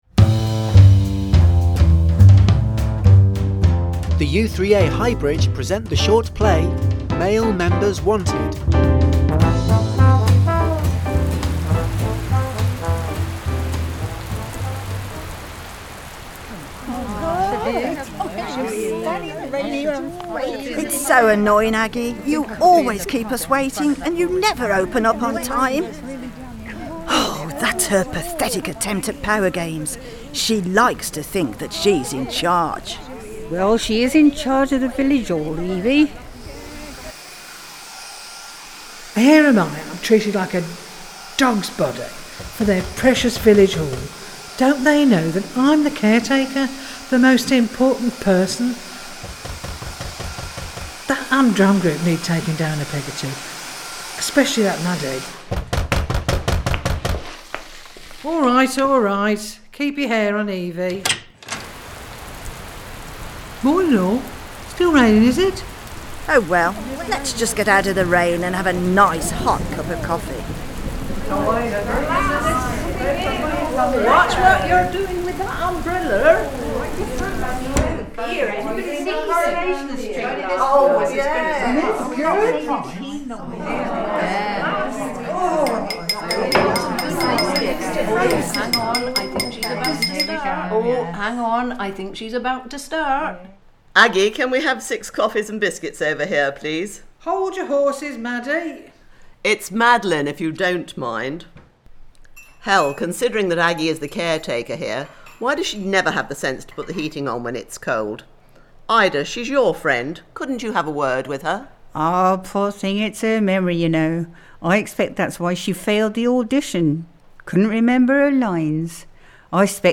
The play was first aired on 10Radio on Tuesday 22nd October 2013.
The Radio Play Only
U3A-radio-play.mp3